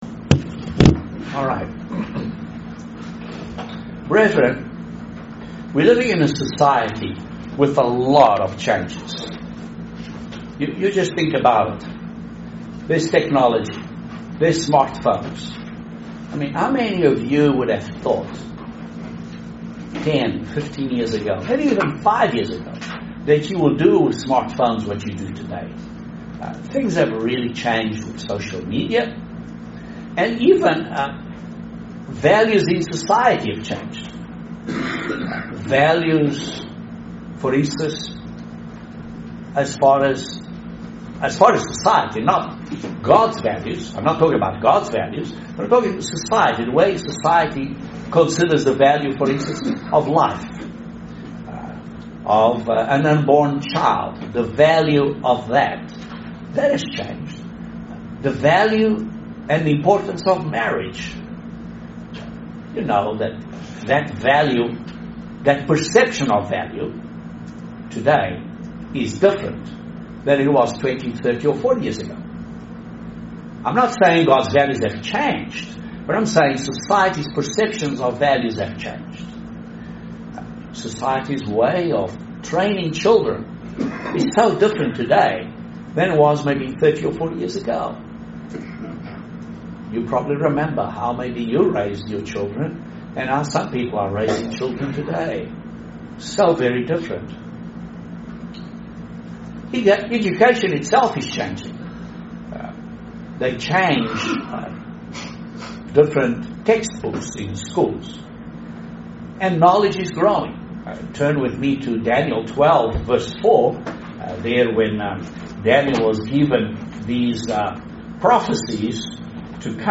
Do we understand our calling? are we a living sacrifice? join us for this interesting video sermon.